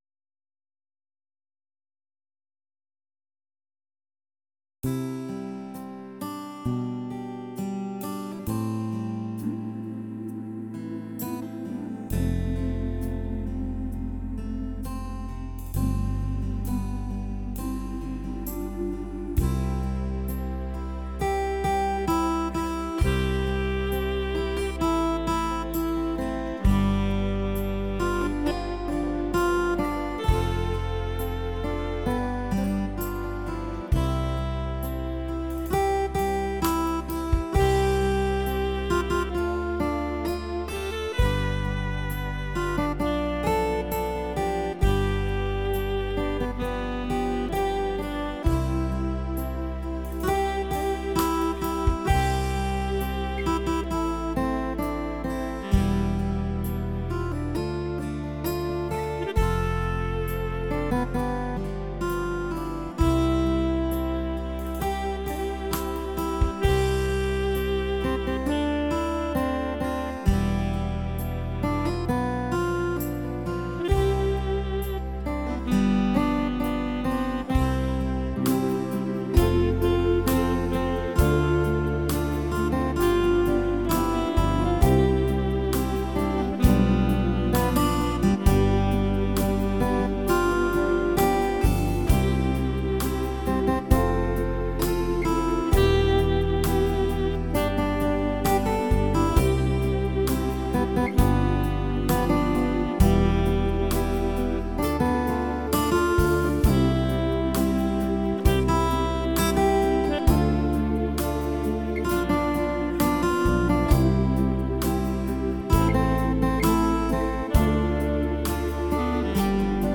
Ao-Vong-sax.mp3